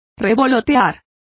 Complete with pronunciation of the translation of hovering.